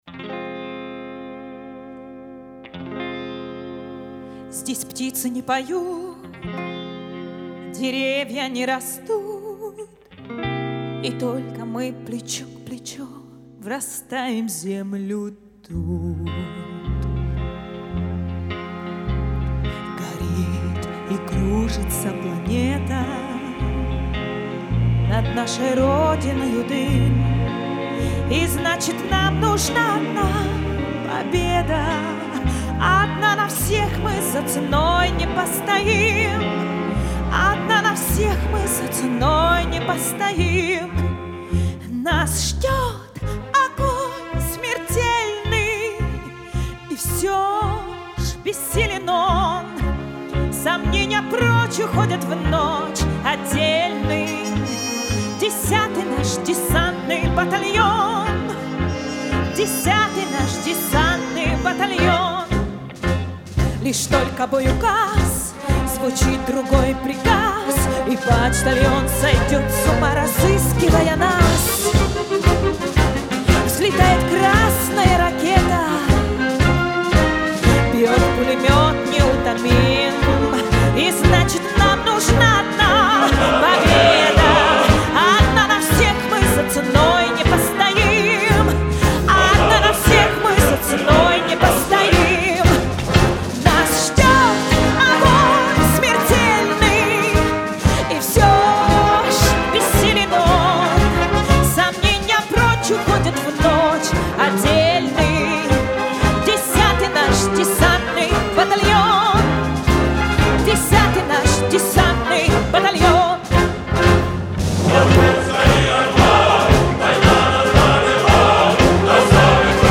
Категория: Военные песни